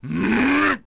deep masculine voice identical to that of Ganondorf's, even with the unique one as Nabooru.
OOT_IronKnuckle_Run.wav